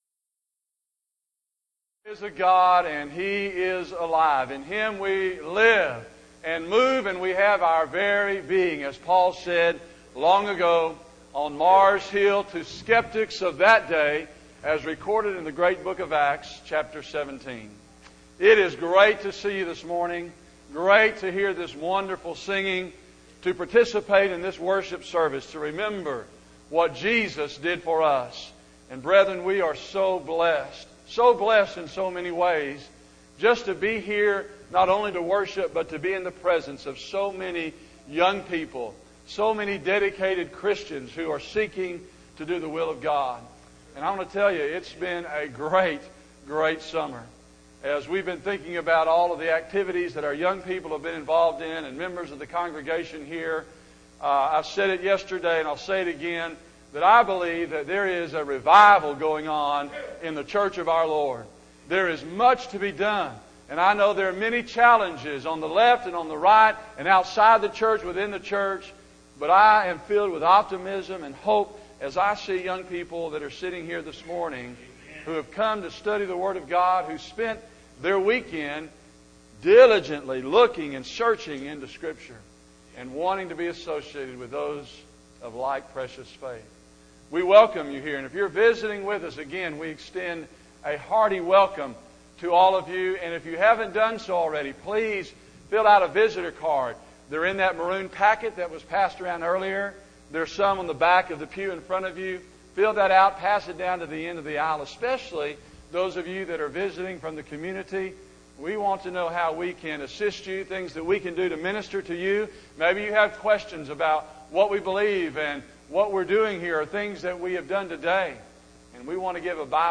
Youth Sessions